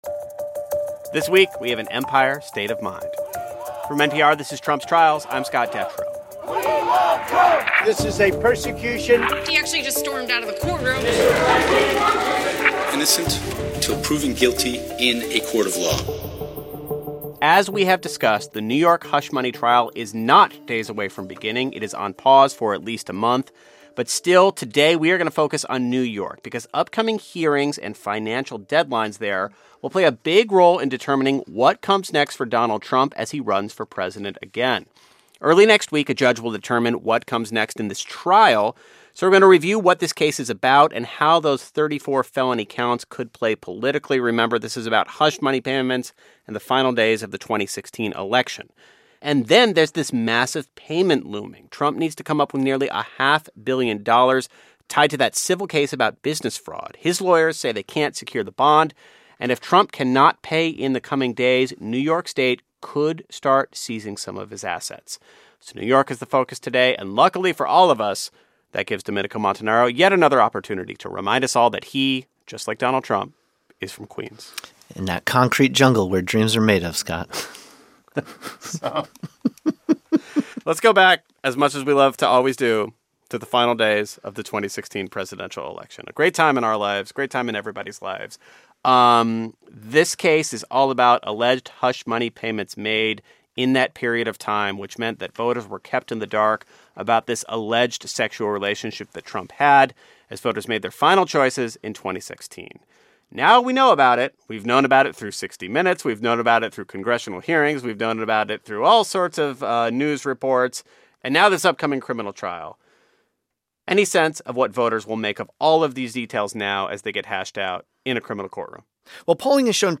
law professor